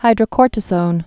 (hīdrə-kôrtĭ-sōn, -zōn)